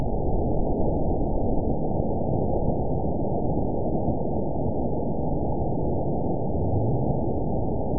event 920886 date 04/13/24 time 05:34:16 GMT (1 year ago) score 9.07 location TSS-AB01 detected by nrw target species NRW annotations +NRW Spectrogram: Frequency (kHz) vs. Time (s) audio not available .wav